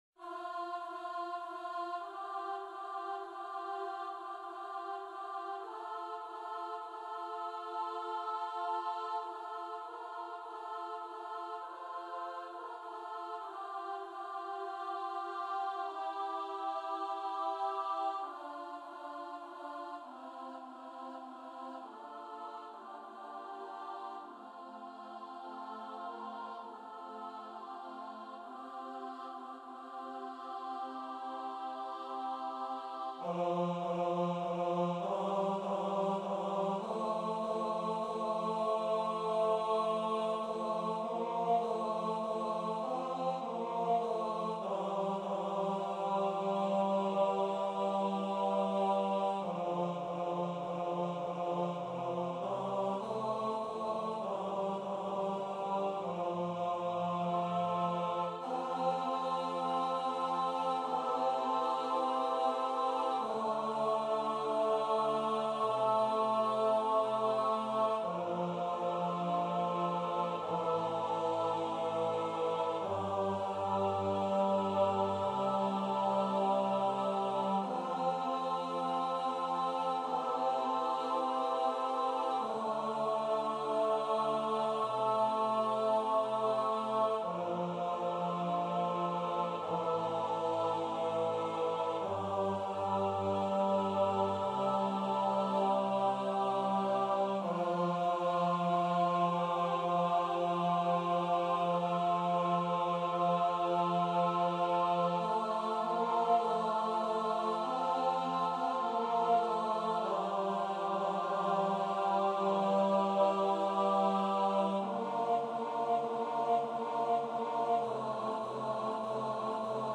- Œuvre pour choeur à 8 voix mixtes (SSAATTBB) a capella
MP3 rendu voix synth.
Tenor 1